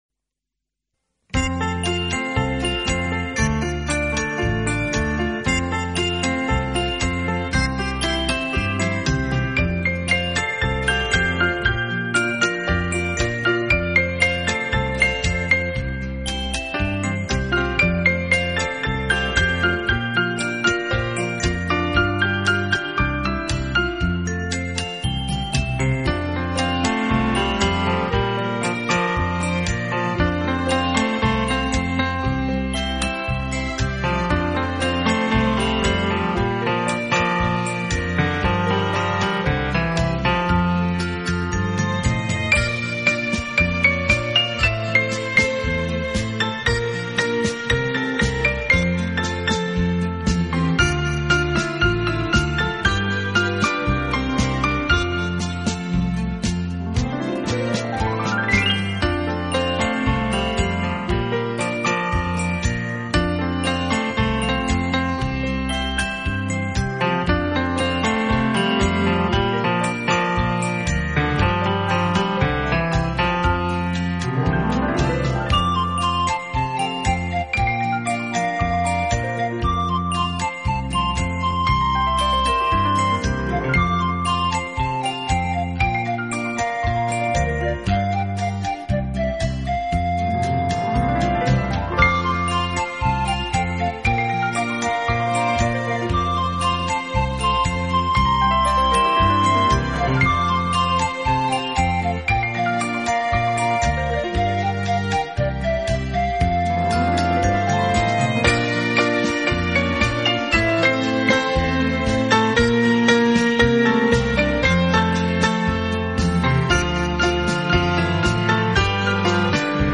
这是一套非常经典的老曲目经过改编用钢琴重新演绎的系列专辑。
本套CD全部钢琴演奏，